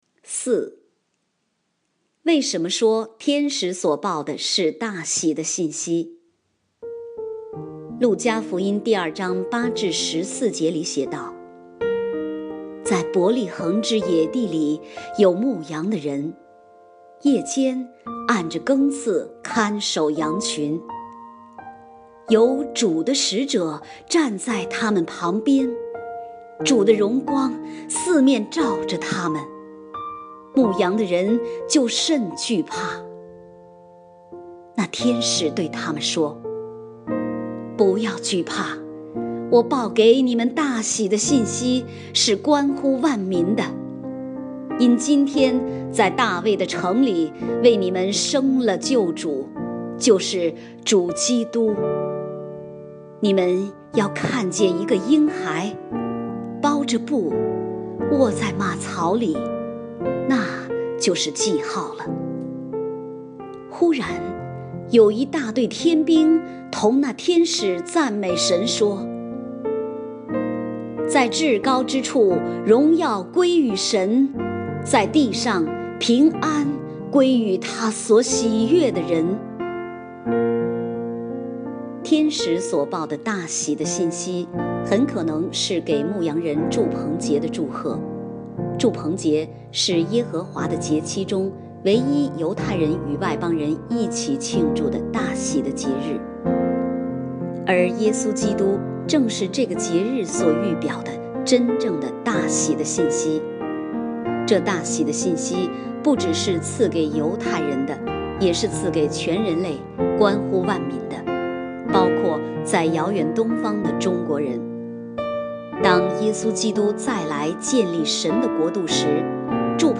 （配乐：是何婴孩 What Child Is This；美哉小城小伯利恒 O Little Town of Bethlehem）